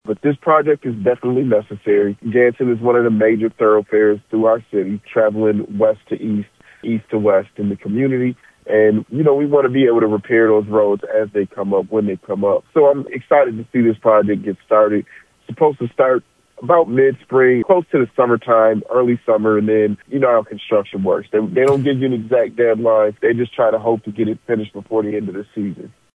Jackson Mayor Daniel Mahoney said that the work is expected to begin in the late spring or early summer, with no currently known deadline of when it is expected to be finished.